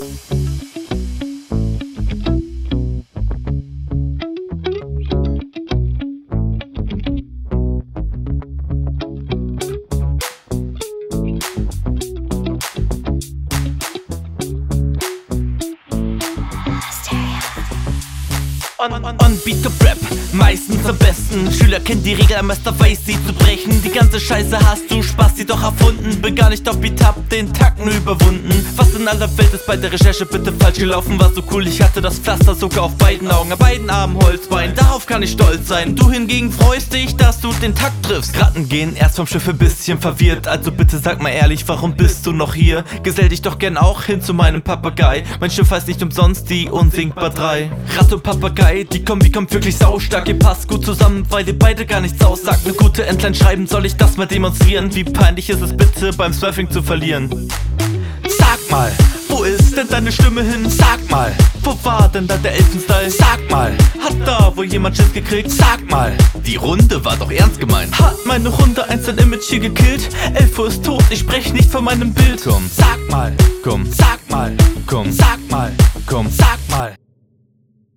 Beat liegt dir allein schon weil Stimme nicht so wie dem Gegner.